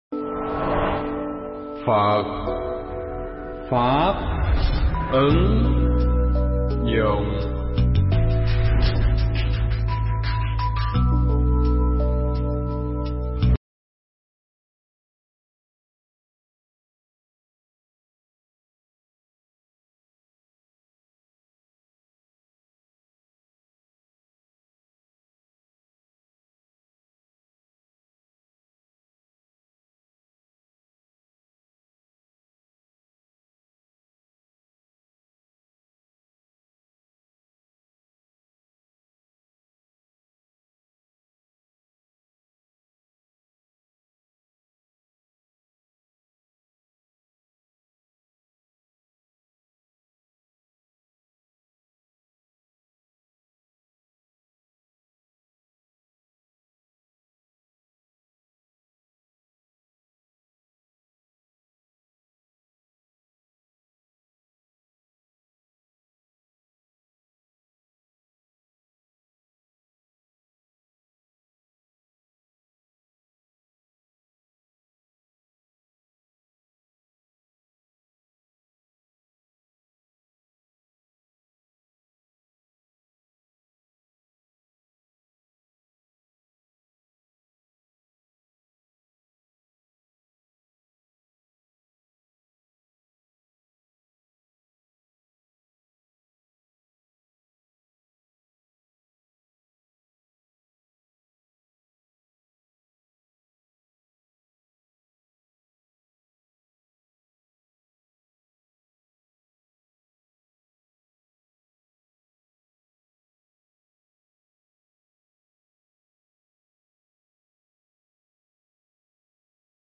Thuyết Giảng
giảng nhân khóa tu một ngày an lạc lần 64 tại Tu Viện Tường Vân